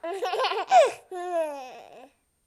baby_laugh1
baby cackle good-mood humour laugh laughter sound effect free sound royalty free Funny